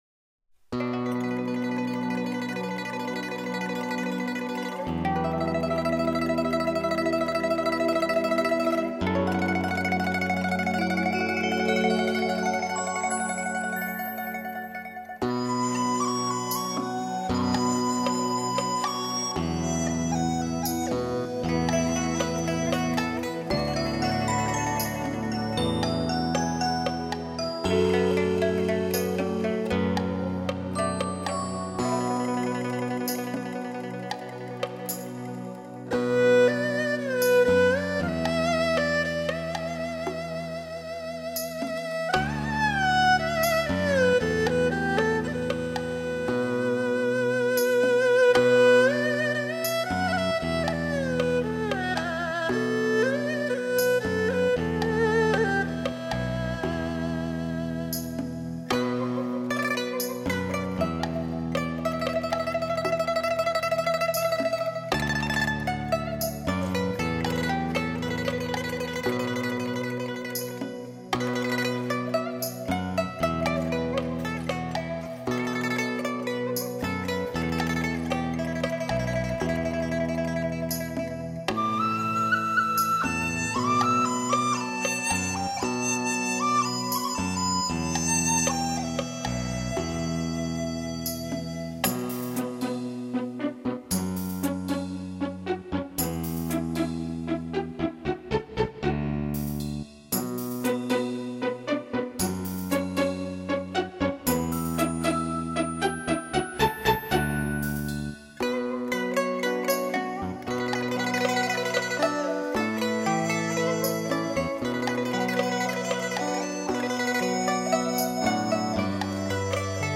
云南汉族民歌: 潺潺小河流水，引出了春意盎然，鸟语花香般的意境。悠扬的旋律，淡雅的色彩，如诗如画，令人无限流连。